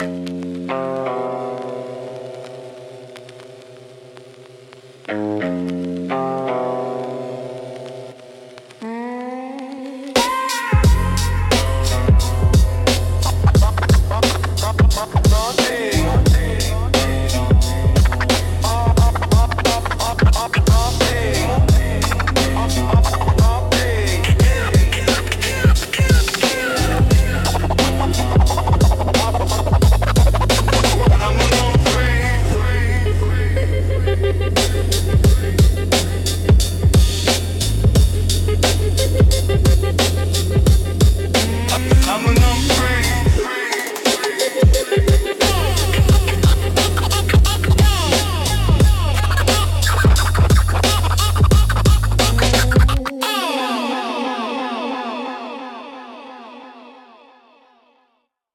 Instrumental - The Scratch in the Static